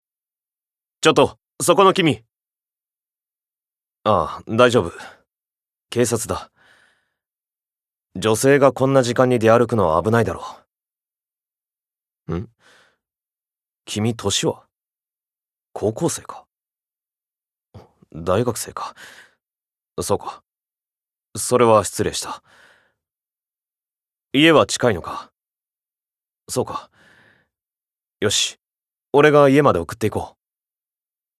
Voice Sample
ボイスサンプル
セリフ１